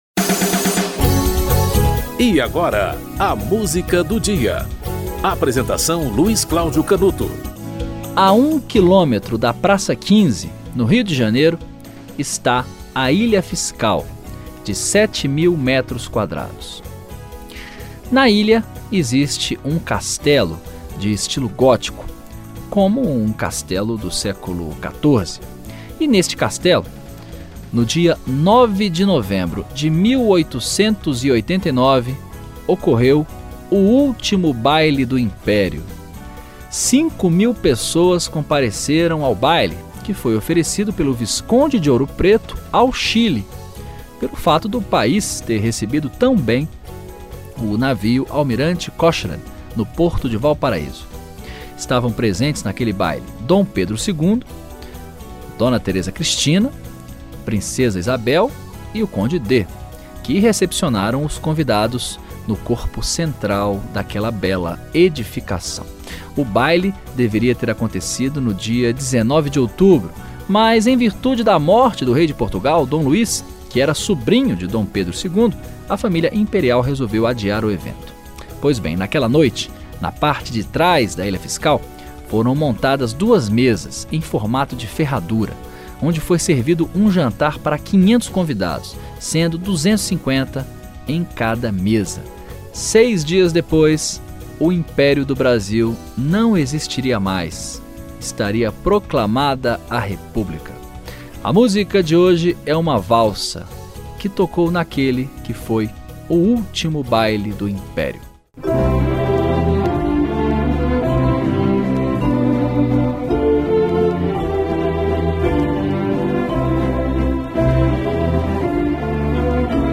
Filarmônica de Berlim - Valsa do Imperador (Johann Strauss II)